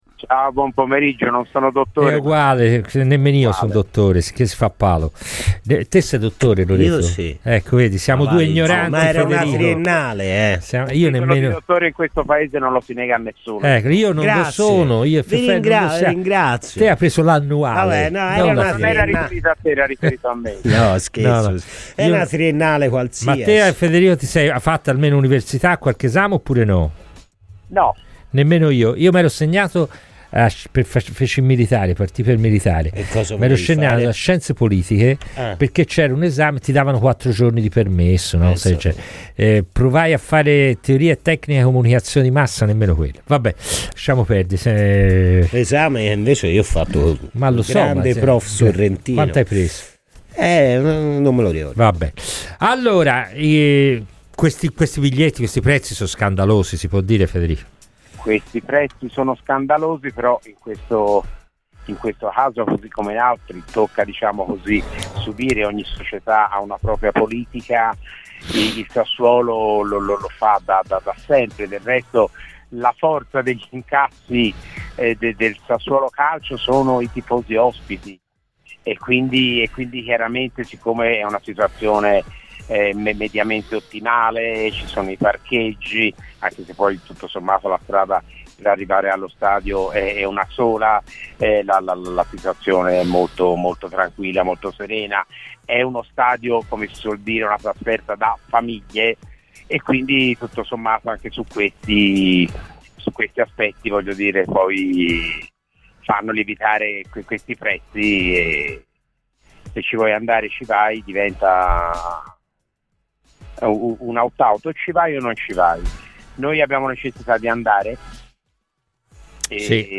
è intervenuto ai microfoni di Radio FirenzeViola nel corso di " Palla al Centro ".